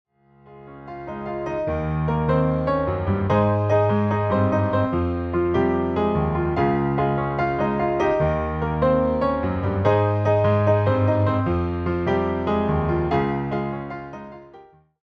reimagined as solo piano arrangements.